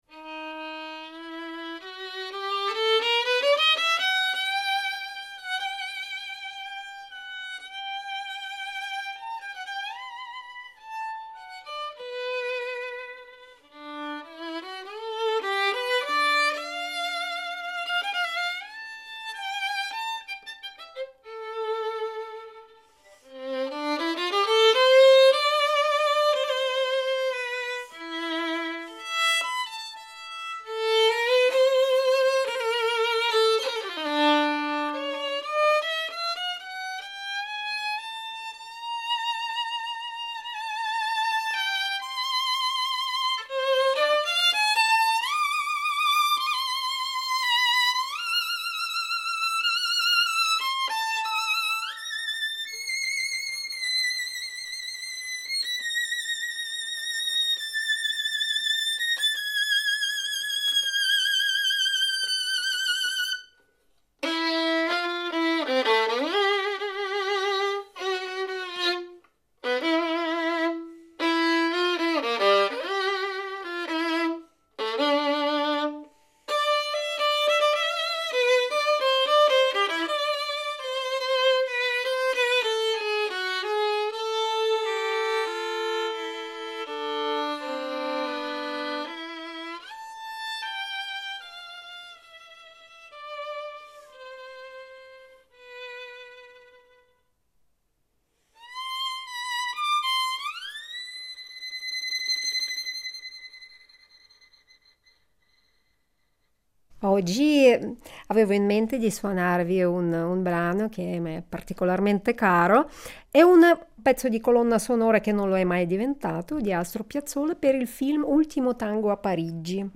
Una serie di interviste per raccontare l’Orchestra della Svizzera italiana